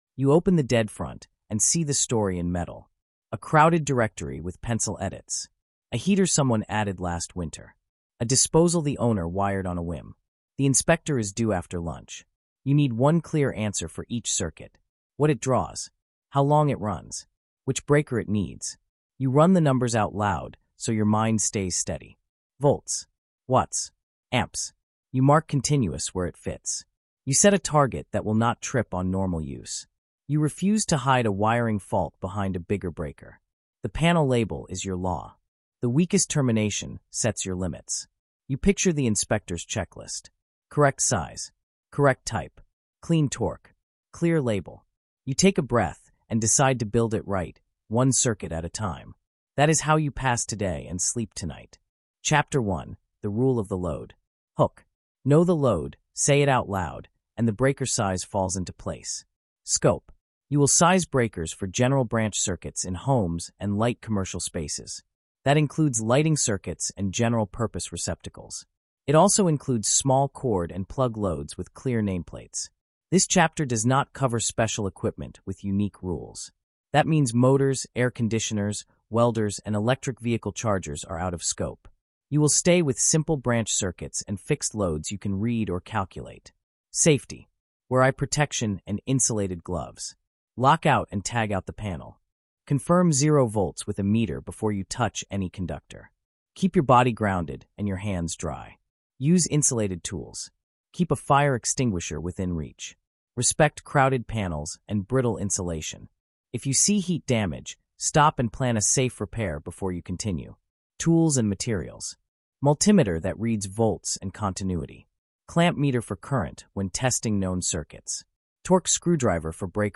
This field manual walks you through load math, ampacity by temperature rating, standard wire to breaker pairs, AFCI and GFCI by room, multiwire safety, and real world derating and voltage drop. Built for working pros and apprentices who want a clean checklist, clear numbers, and a steady voice.